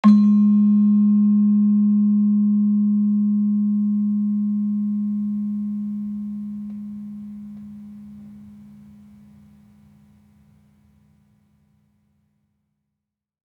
Gamelan Sound Bank
Gender-1-G#2-f.wav